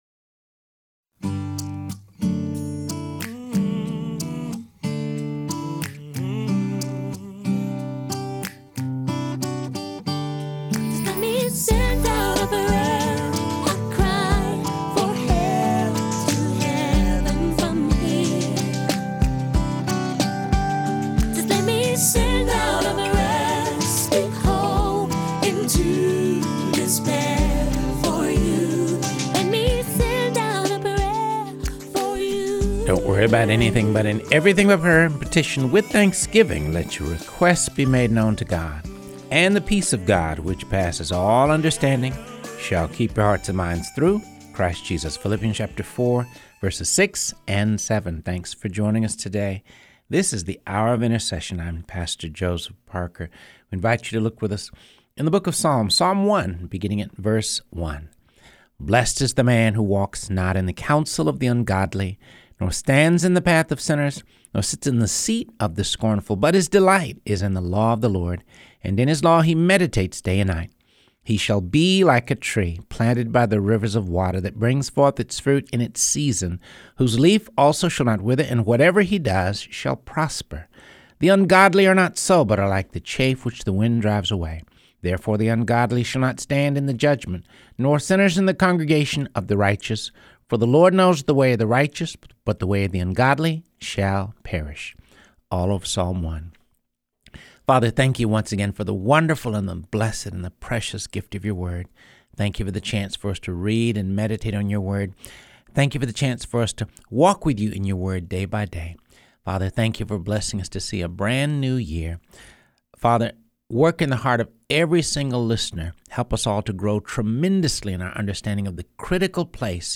reading through the Bible.